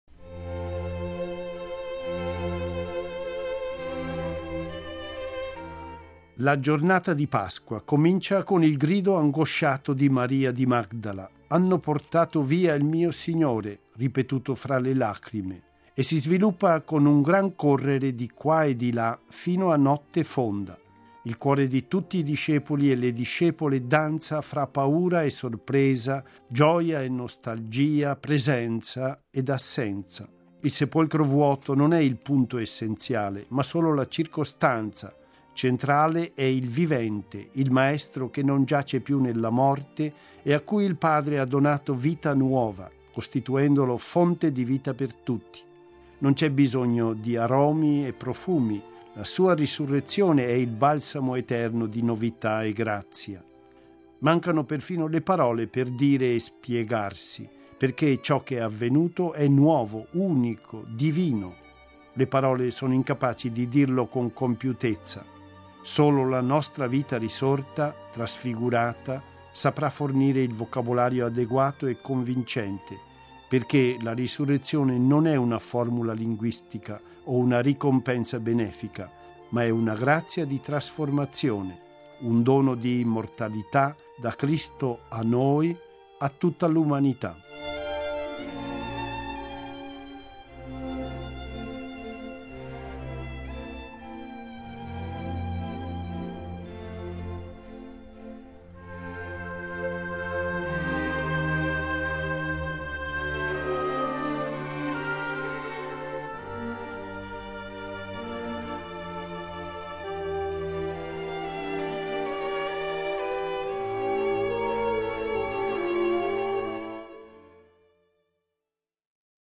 Il commento